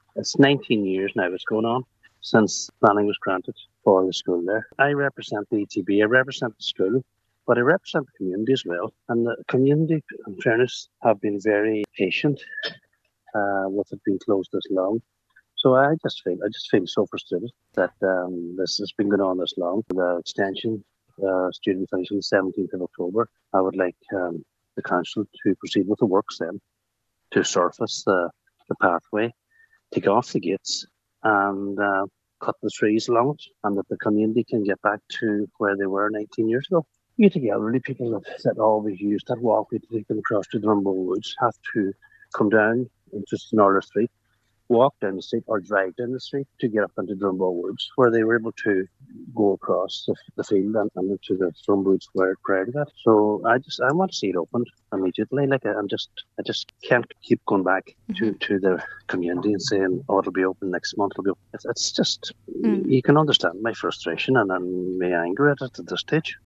However, Cllr Martin Harley fears if the walkway isn’t reopened now, it may never reopen, and that’s not acceptable: